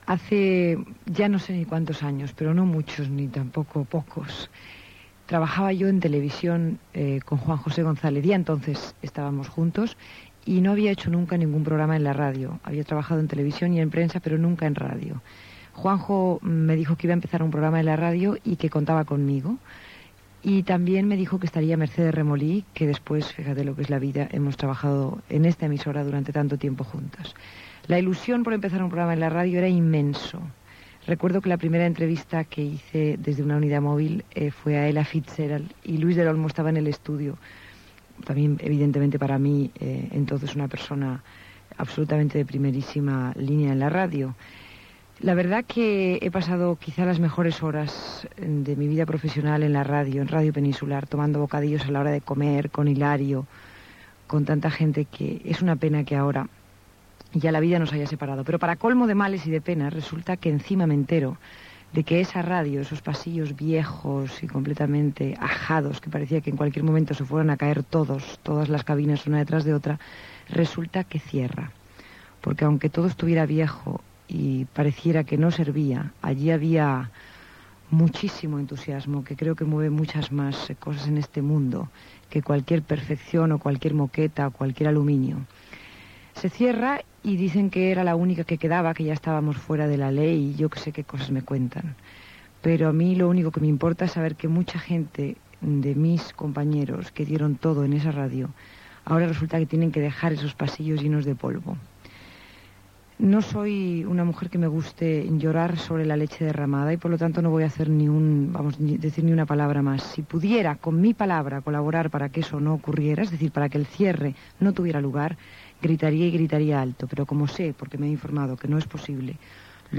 Tancament de l'emissora. Paraules de Mercedes Milá.